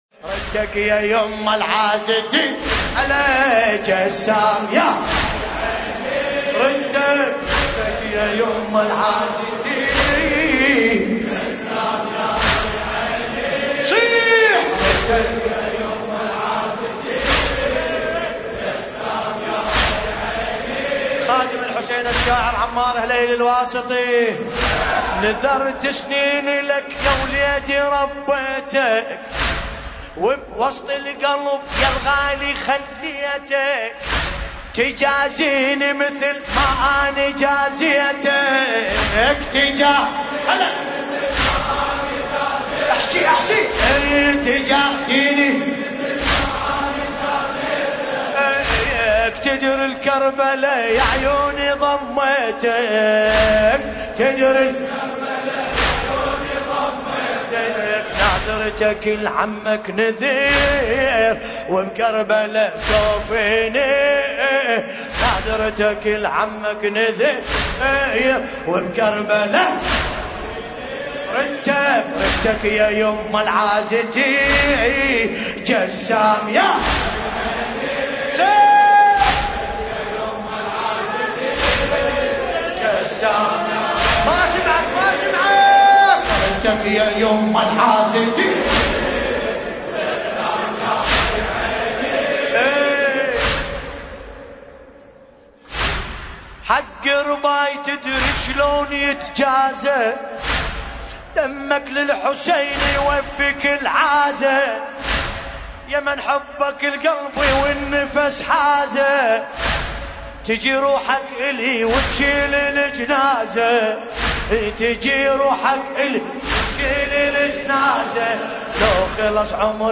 المناسبة : ليلة 8 محرم 1441ه المكان : ديوان الكفيل لندن